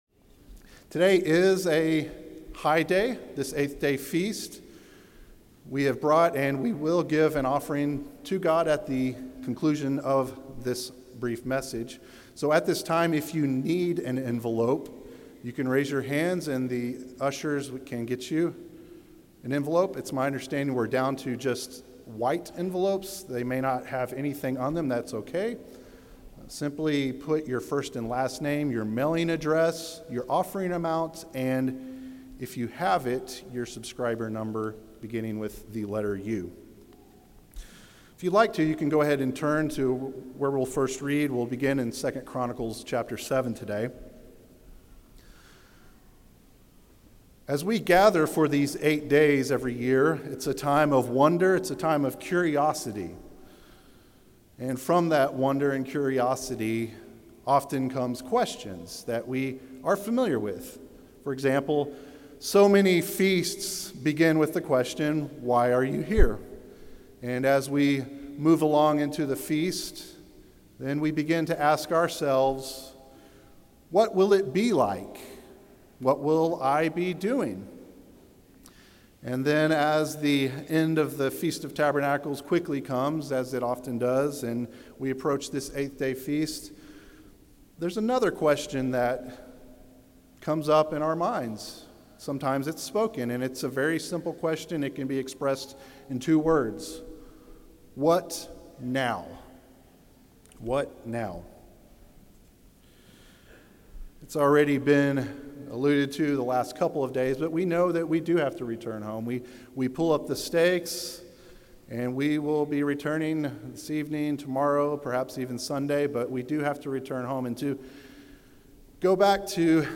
This sermon was given at the Gatlinburg, Tennessee 2024 Feast site.